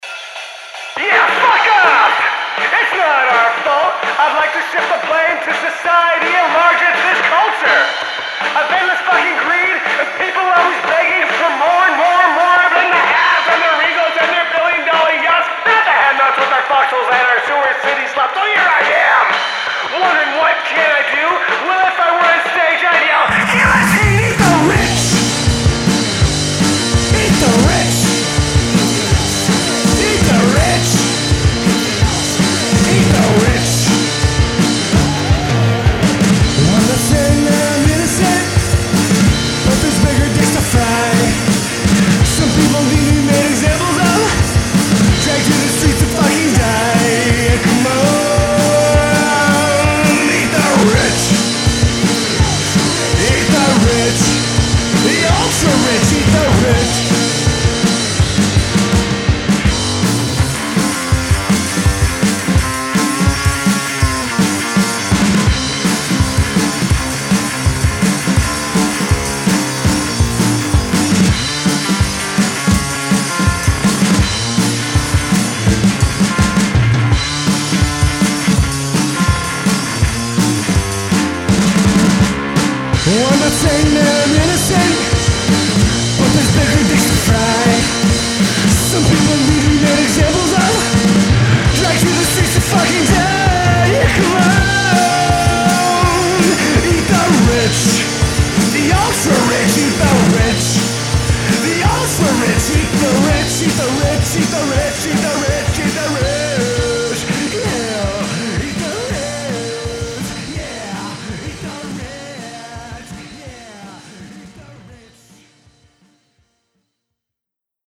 gutterfuzz duo blending raw DIY grunge
bass
drums